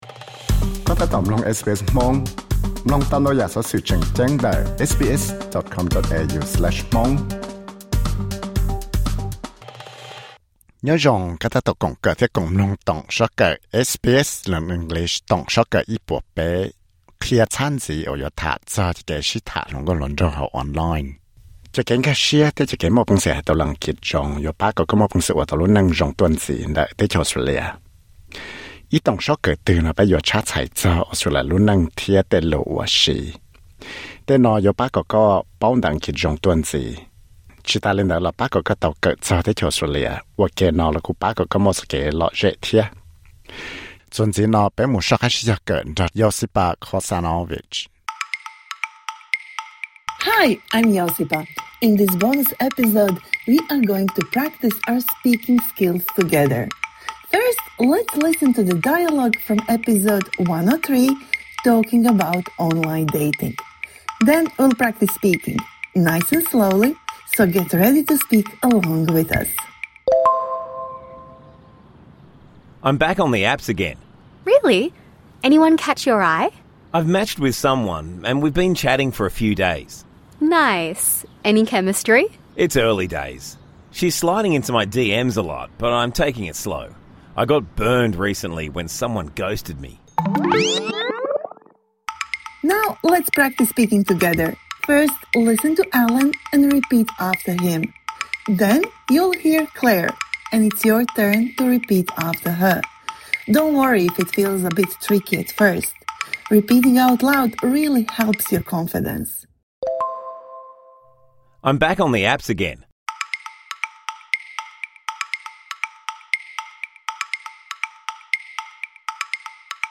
Learn the meaning of the phrases used in this dialogue